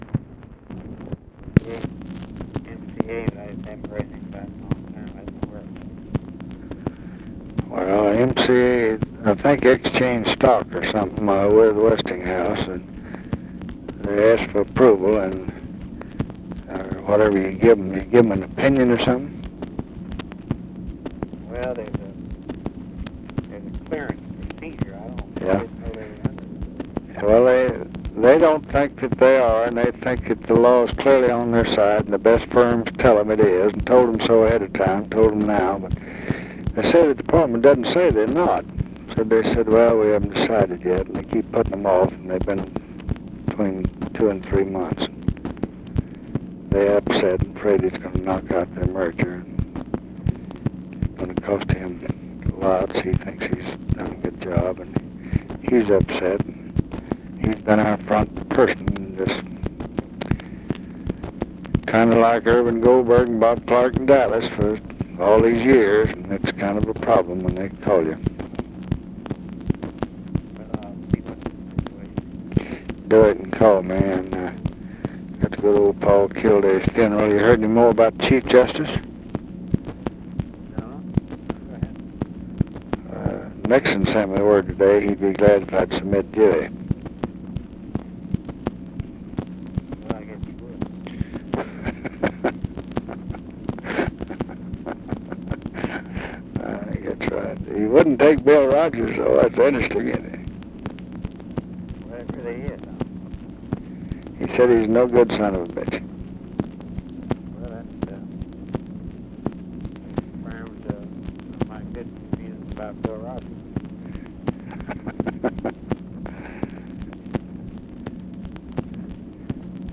Conversation with RAMSEY CLARK, October 15, 1968
Secret White House Tapes